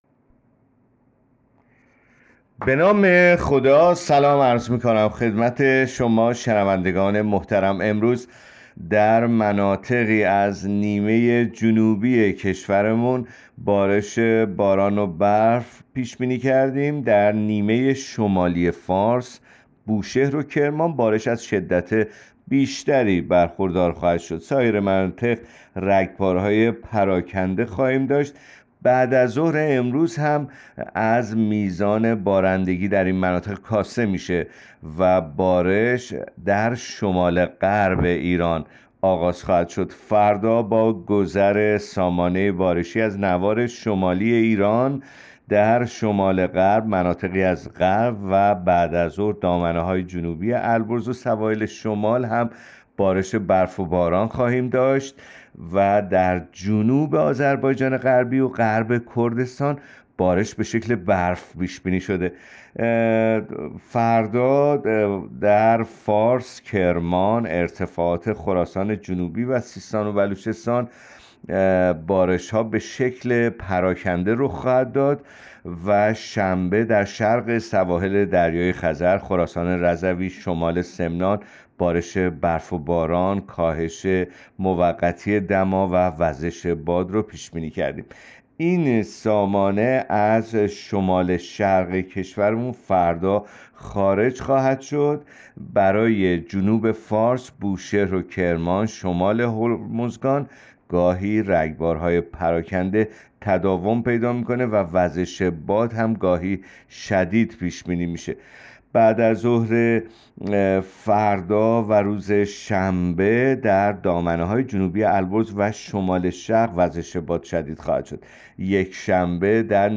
گزارش رادیو اینترنتی پایگاه‌ خبری از آخرین وضعیت آب‌وهوای ۲۵ بهمن؛